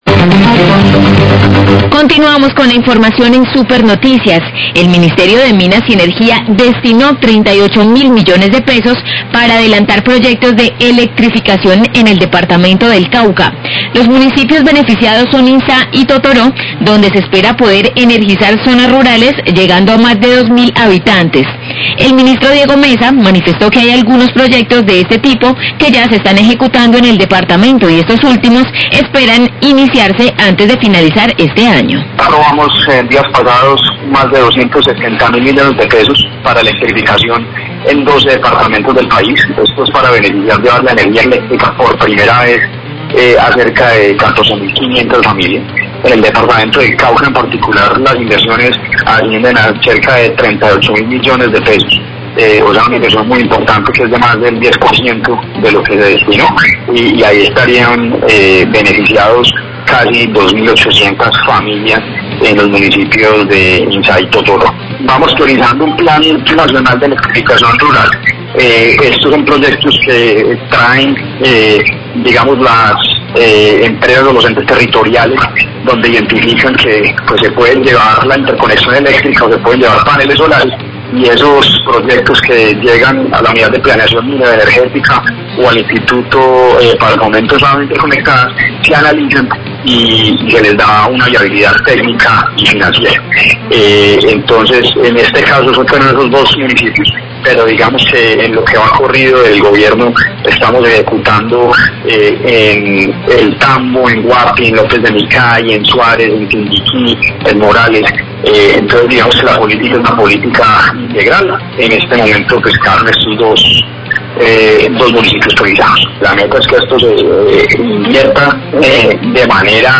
Radio
El Ministerio de Minas y Energía destinó recursos por un monto de 36 mil millones para un proyecto de electrificación rural en los municipios de Inzá y Totoró. Declaraciones del Ministro de Minas y Energía, Diego Mesa, sobre otros proyectos de electrificación en el Cauca.